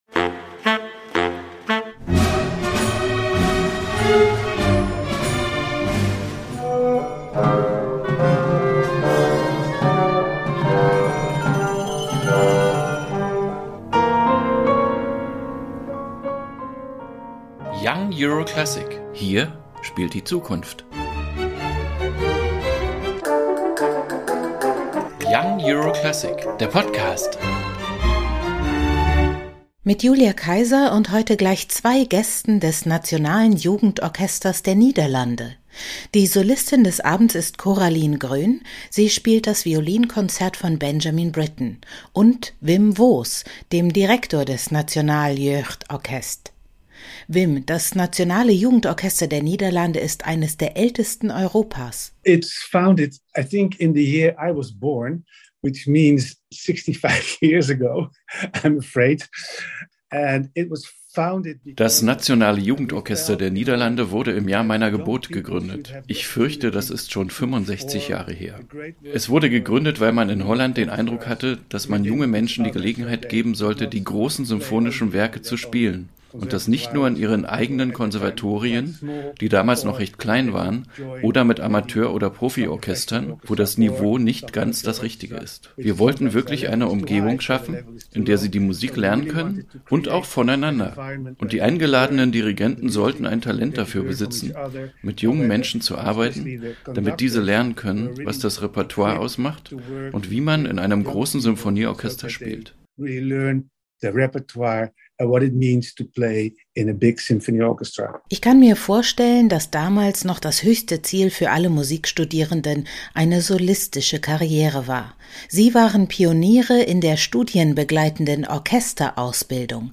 Konzerteinführung 21.08.2022